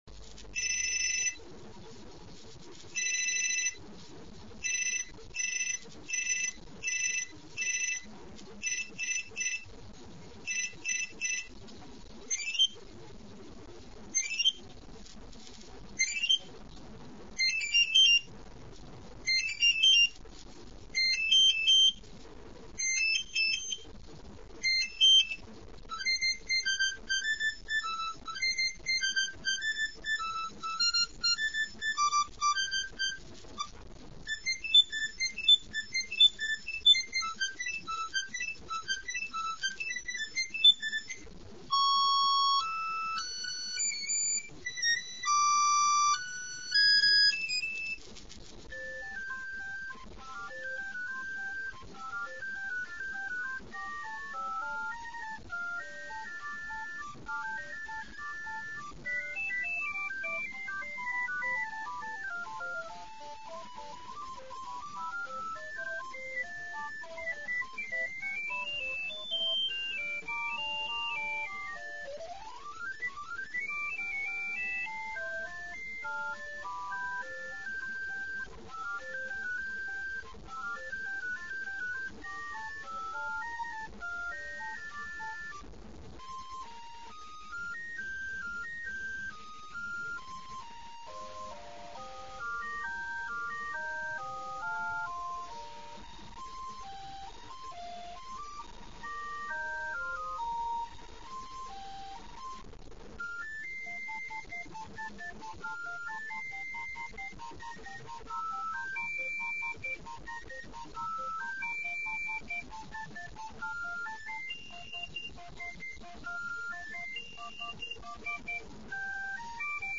В вашем распоряжении 21 тип звонка и вы можете выбрать себе тон или мелодию по вкусу (